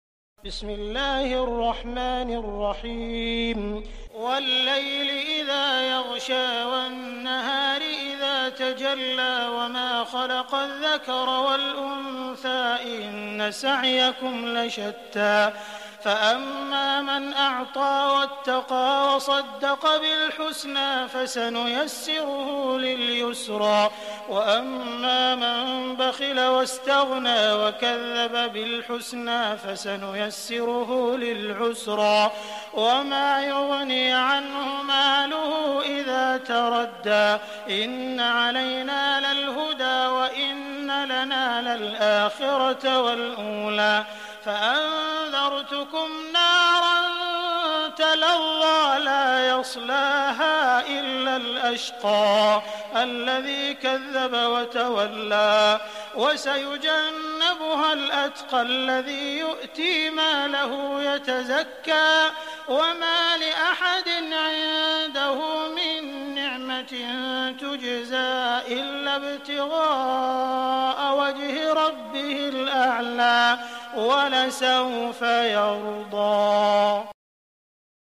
Click the play button below to start listening to an Arabic beautiful voice: